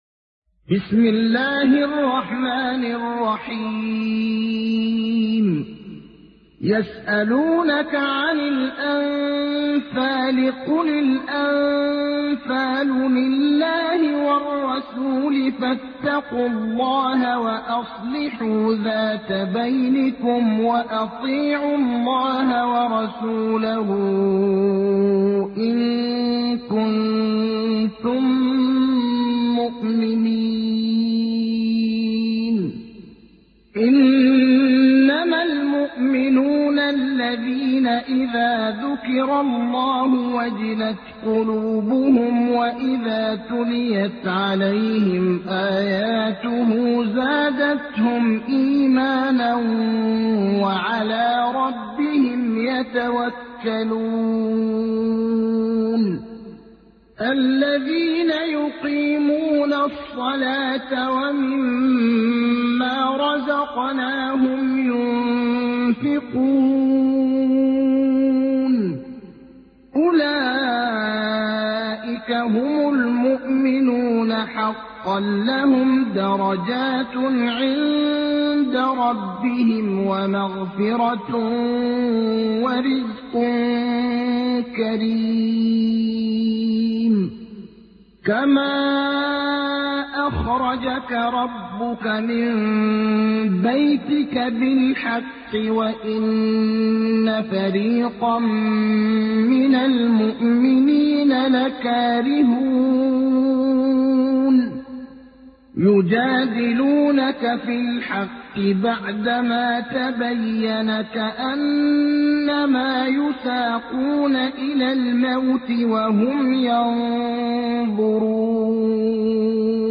تحميل : 8. سورة الأنفال / القارئ ابراهيم الأخضر / القرآن الكريم / موقع يا حسين